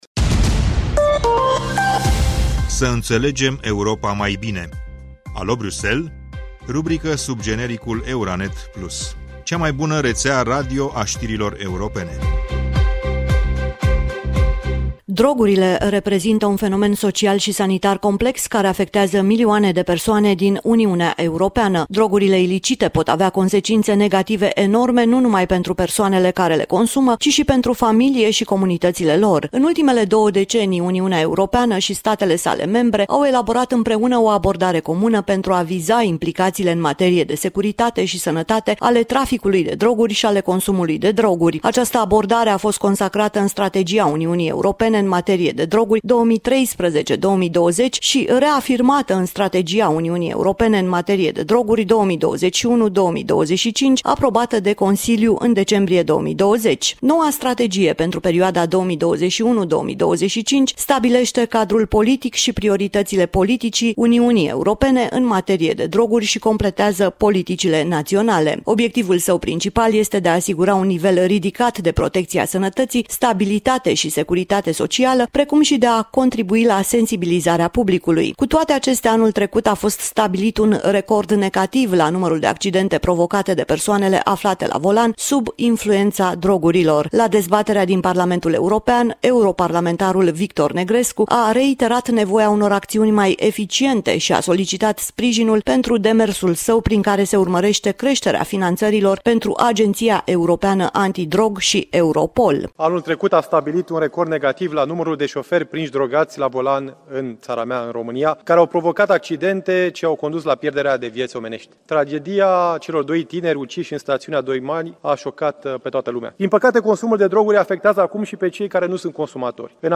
La dezbaterea din Parlamentul Europen, europarlamentarul Victor Negrescu, a reiterat nevoia unor acțiuni mai eficiente și a solicitat sprijinul pentru demersul său prin care se urmărește creșterea finanțărilor pentru agenția europeană anti-drog și Europol.